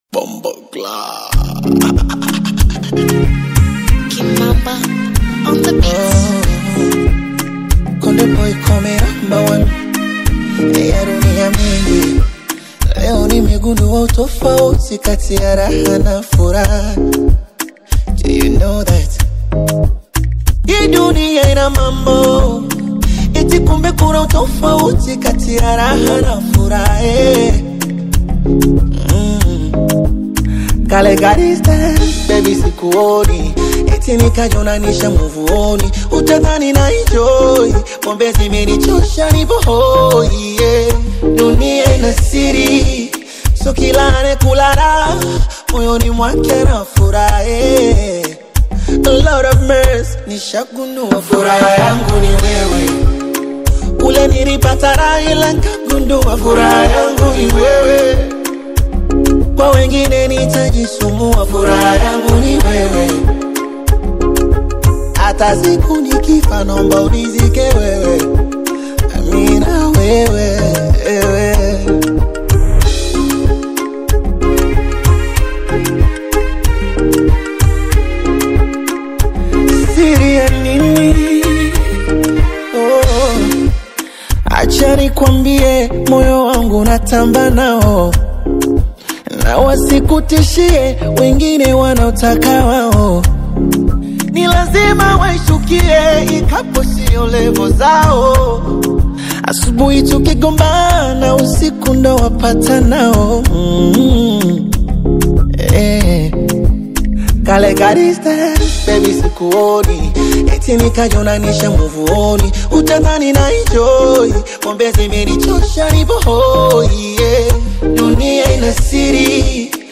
Popular Tanzanian urban music